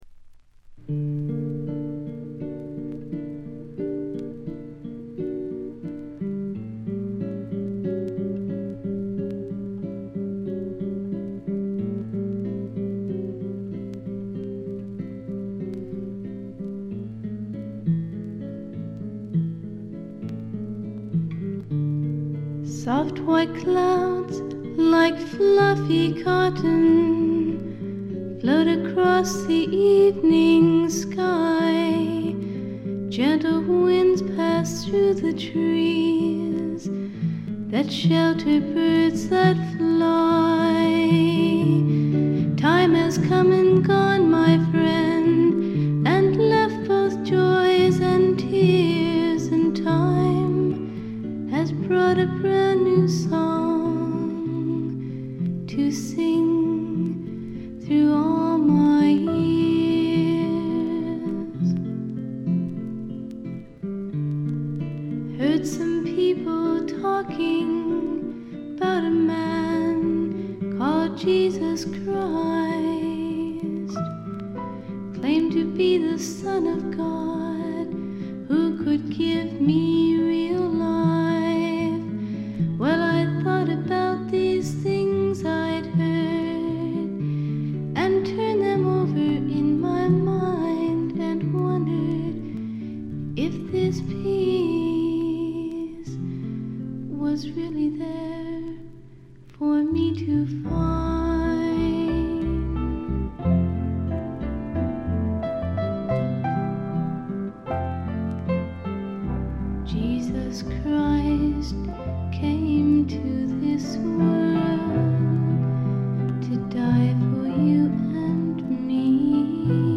静音部でバックグラウンドノイズ。
演奏はほとんどがギターの弾き語りです。
試聴曲は現品からの取り込み音源です。